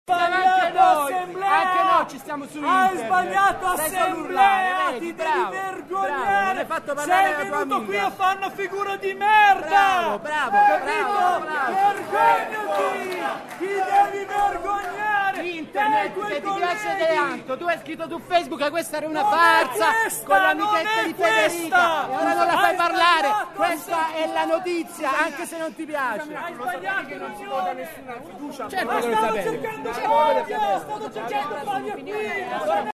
Alla fine dell’assemblea, quando i gionalisti si sono avvicinati a Salsi per porle alcune domande, c’è stata un po’ di tensione con una parte dell’assemblea che si è messa a gridare “vergogna” all’indirizzo della stampa.